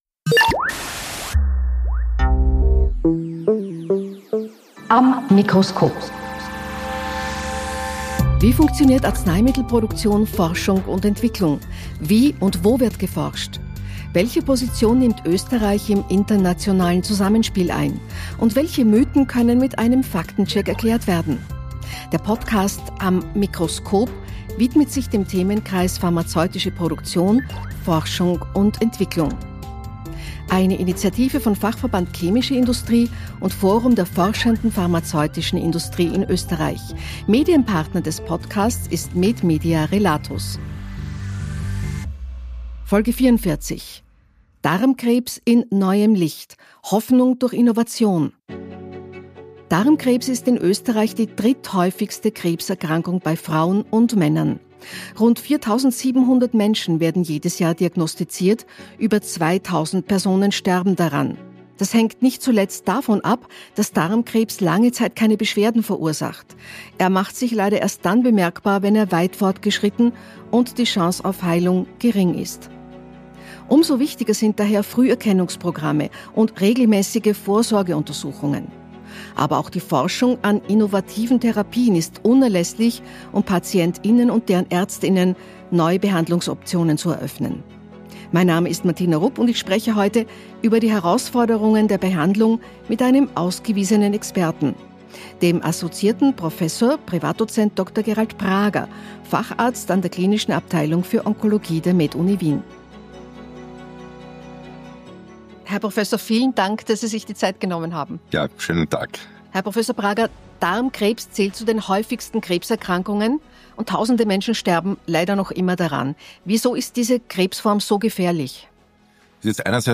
In welche Richtung die Forschung aktuell geht, darüber spricht Moderatorin Martina Rupp in der 44.